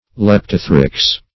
leptothrix - definition of leptothrix - synonyms, pronunciation, spelling from Free Dictionary
Leptothrix \Lep"to*thrix\ (l[e^]p"t[-o]*thr[i^]ks), n. [NL., fr.